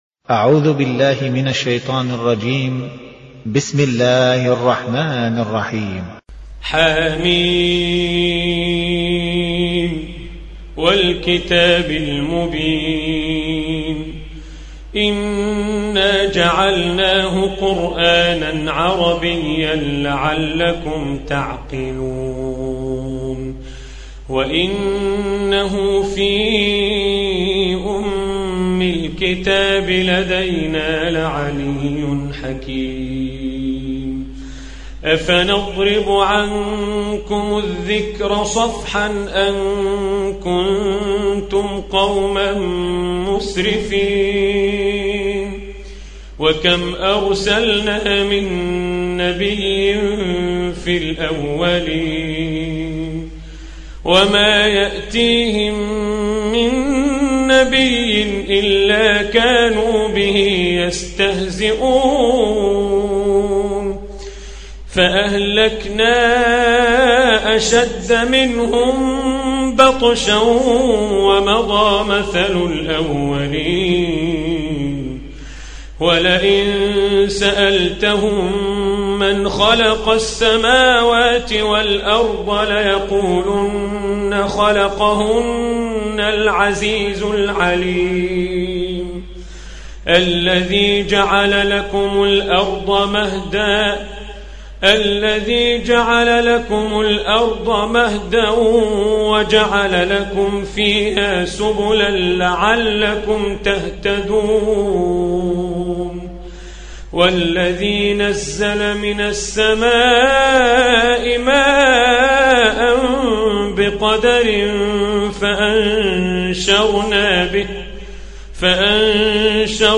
Surah Repeating تكرار السورة Download Surah حمّل السورة Reciting Murattalah Audio for 43. Surah Az-Zukhruf سورة الزخرف N.B *Surah Includes Al-Basmalah Reciters Sequents تتابع التلاوات Reciters Repeats تكرار التلاوات